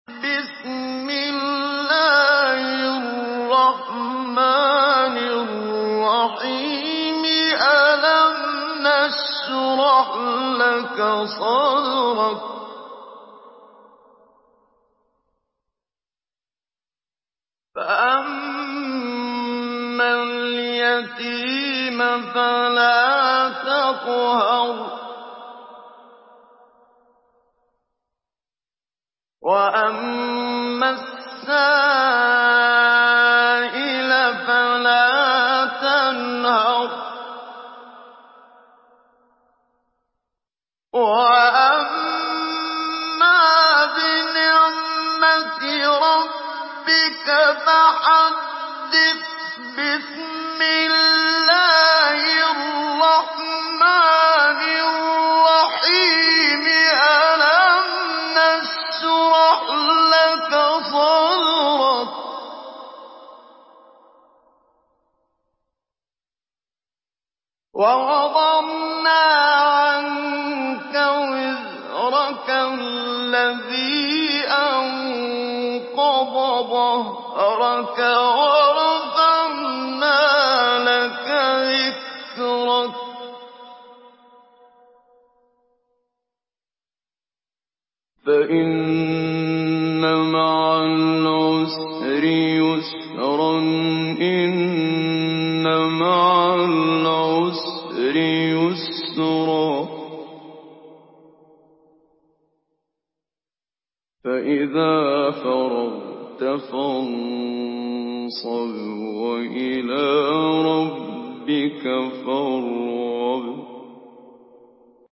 Surah Ash-Sharh MP3 in the Voice of Muhammad Siddiq Minshawi Mujawwad in Hafs Narration
Mujawwad